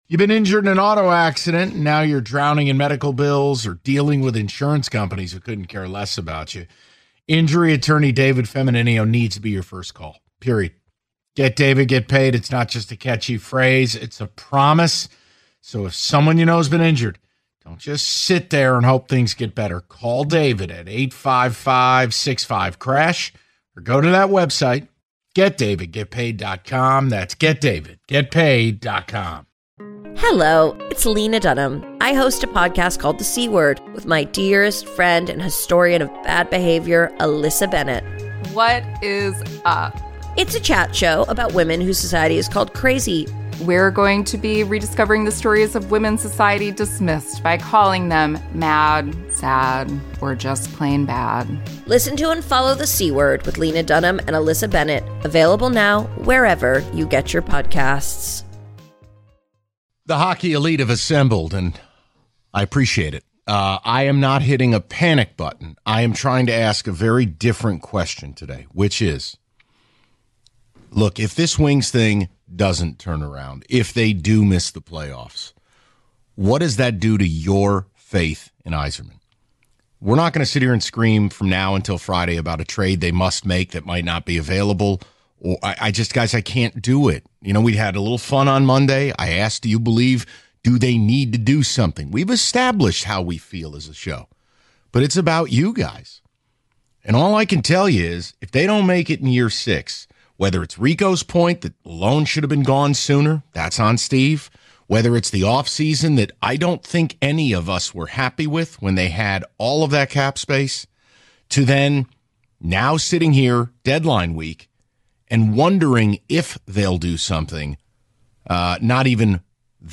The Hockey Elite Chime In On Yzerman Concerns The Valenti Show Audacy Sports 3.8 • 1.1K Ratings 🗓 5 March 2025 ⏱ 11 minutes 🔗 Recording | iTunes | RSS 🧾 Download transcript Summary The guys take some calls from the hockey elite to hear what their thoughts are regarding Steve Yzerman as Red Wings GM.